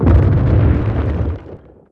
explo6.wav